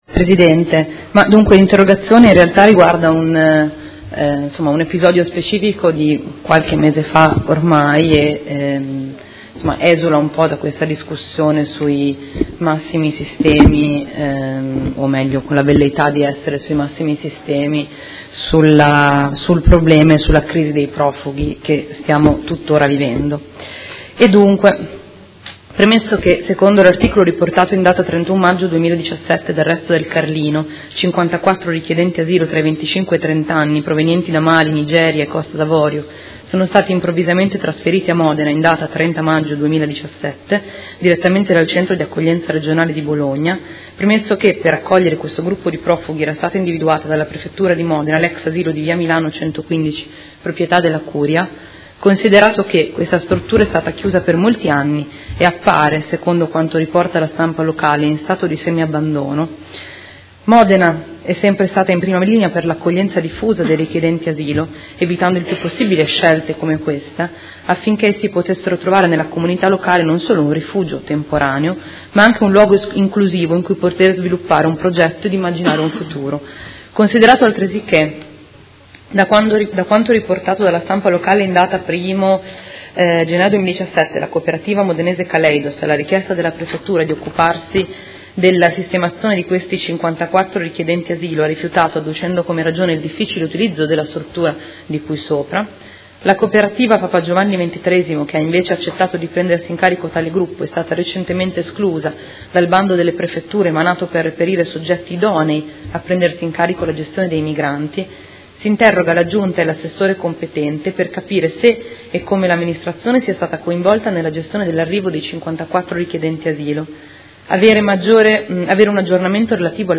Interrogazione dei Consiglieri Di Padova e Fasano (PD) avente per oggetto: Profughi presso struttura in Via Milano